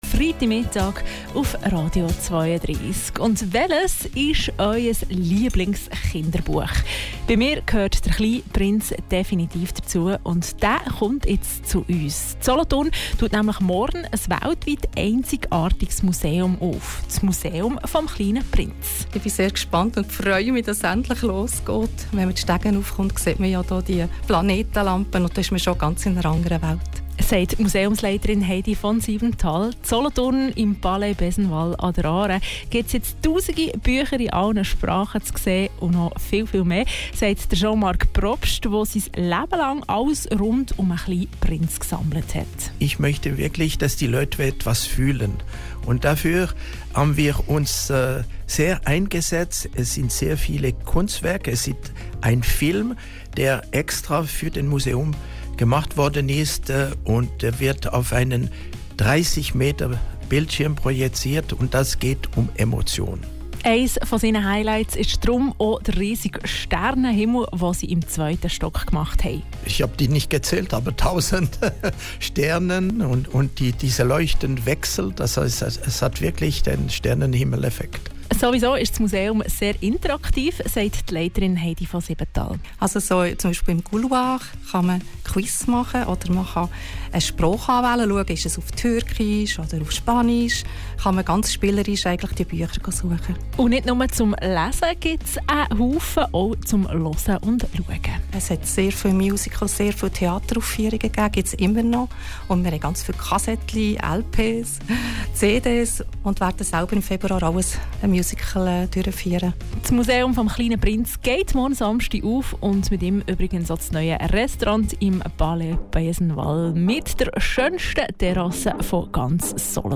Kurzbericht Eröffnung